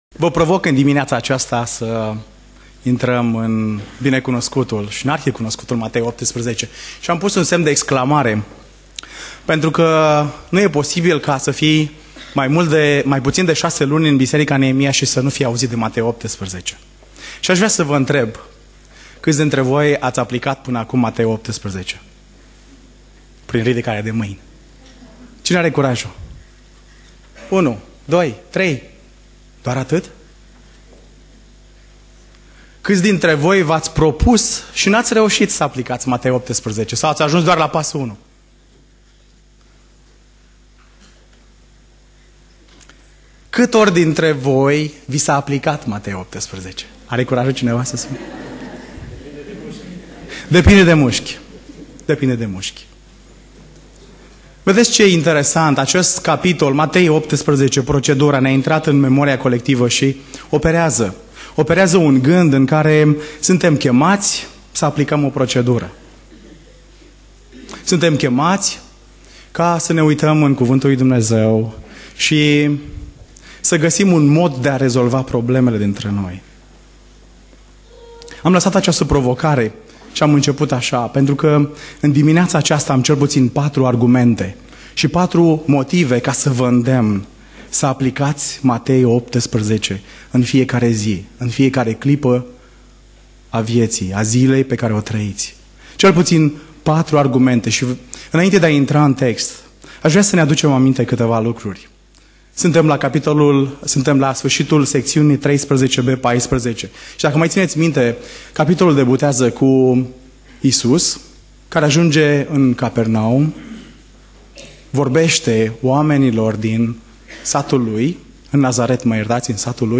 Predica Exegeza - Matei 18